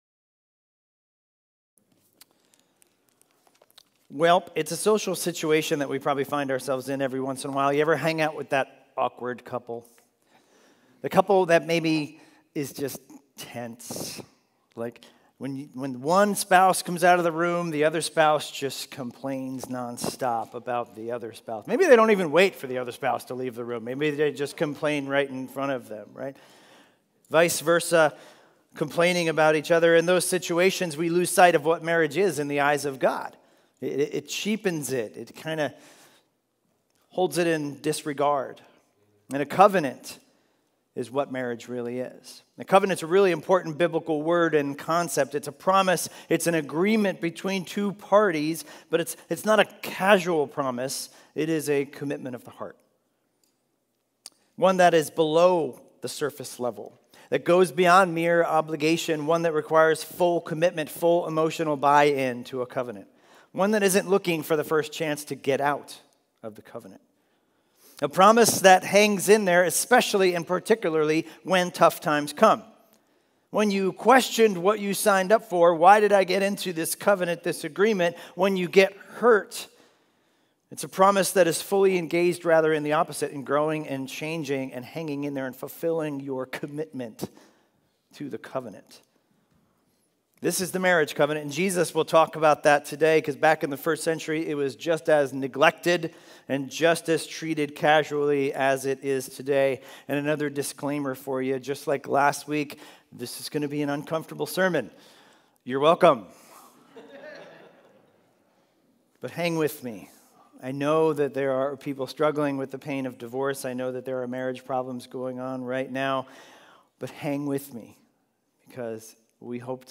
Sermons – Highlands Bible Church
Expositional teaching series through the book of Matthew - starting Sunday, Dec 6, 2020